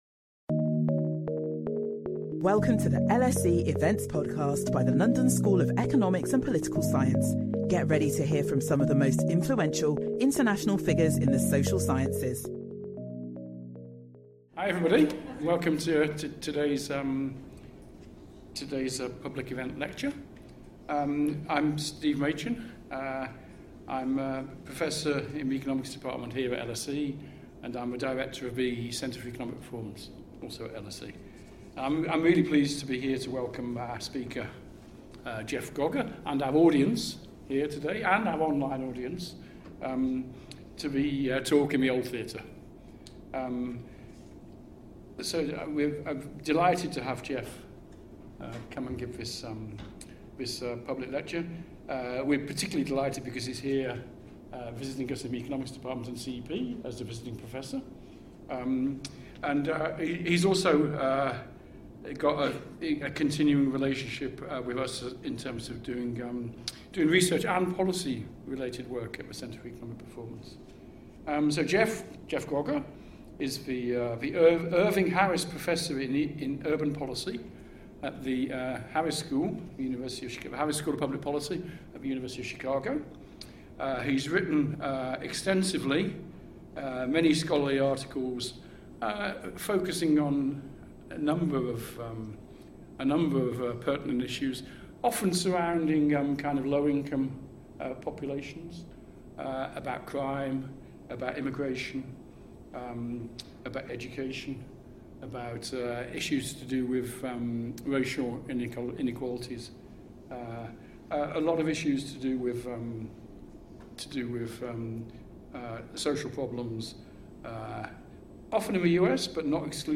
This lecture presents findings from three studies examining the risk assessment process which has been used across England since 2009 to help police identify victims at high risk of serious repeat abuse and connect them with protective services.